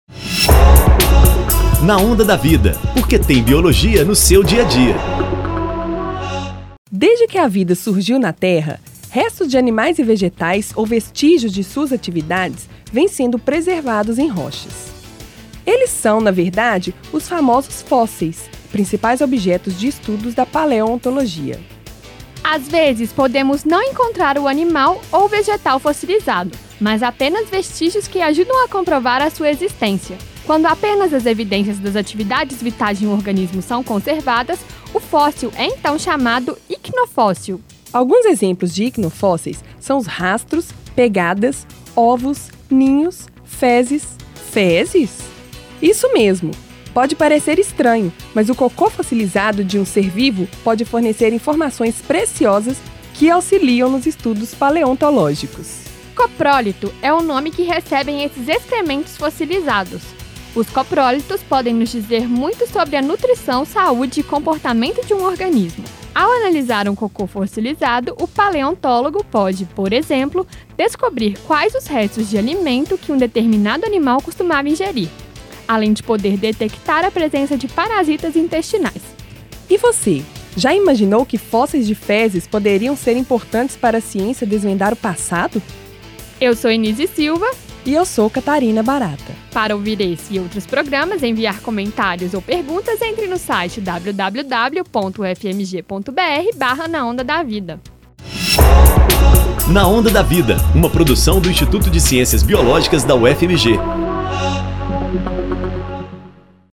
Na Onda da Vida” é um programa de divulgação científica através do rádio